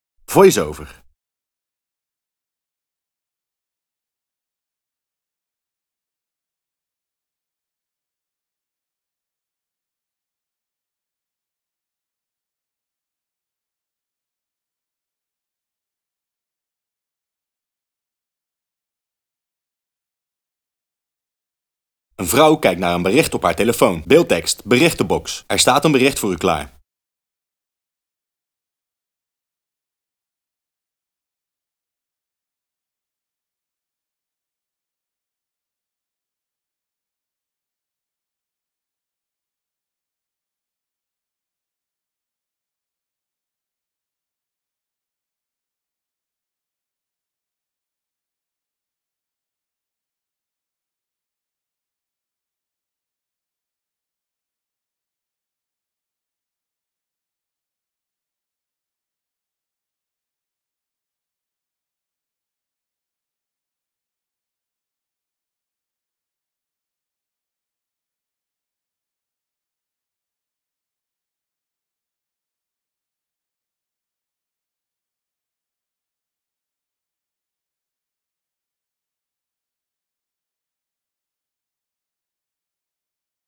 Audio-descriptie